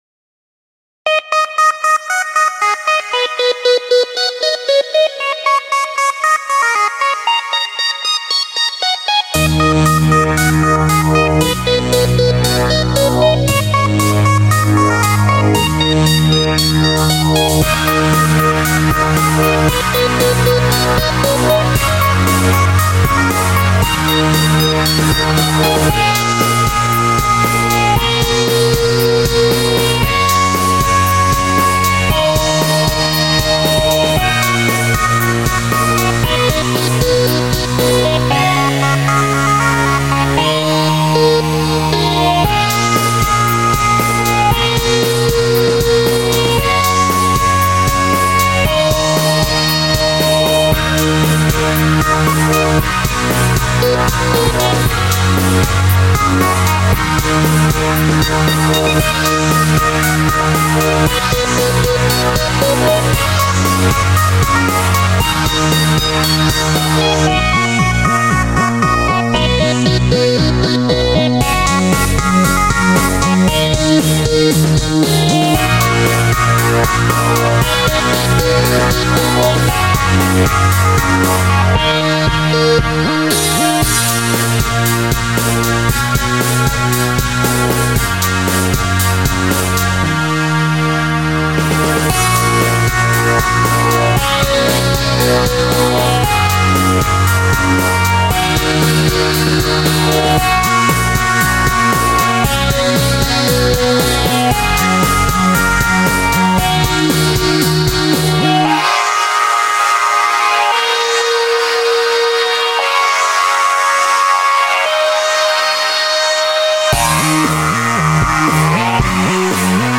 Žánr: Electro/Dance
NÁLADA ALBA JE PŘEVÁŽNĚ MELONCHOLICKÁ.